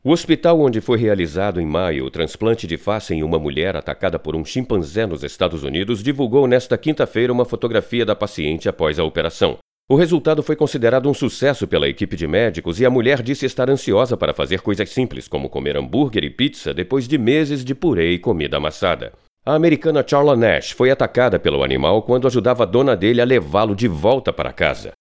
Masculino
Demo de Locução Jornalística
Voz Madura